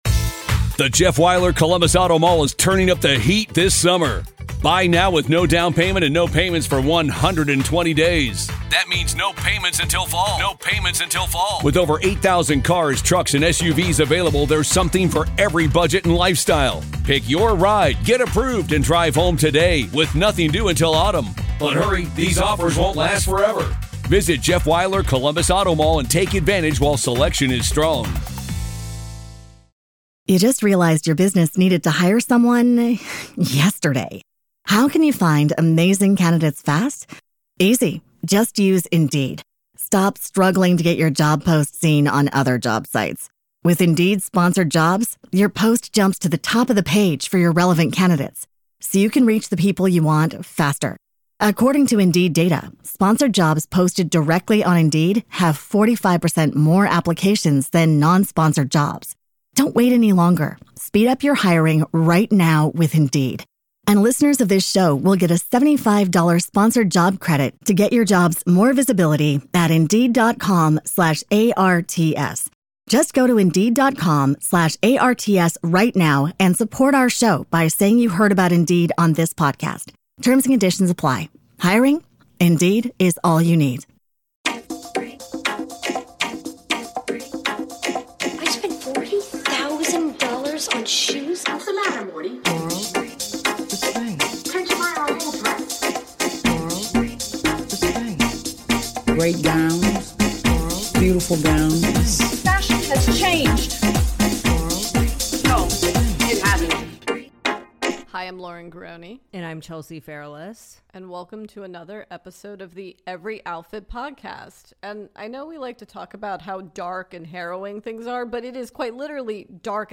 The ladies are back once again for a curated discussion of the best in pop culture.